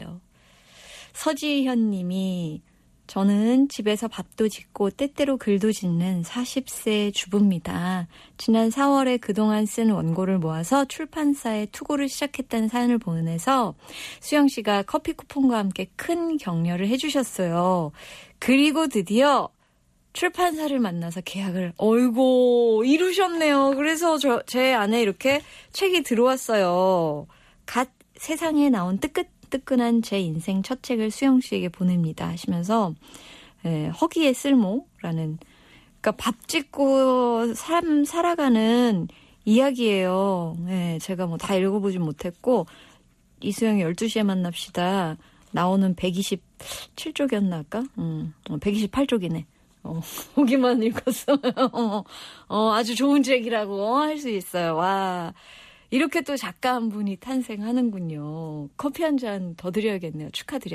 CBS 음악 FM '이수영의 12시에 만납시다'에 제가 보낸 손편지가 소개되었습니다.
수영 언니가 이 꼭지를 읽으셨다고, 그러면서 "이런 책은 너무나 훌륭한 책인 것입니다"라고 분명히 말하는데, 온몸을 타고 도는 이 짜릿한 감동이란!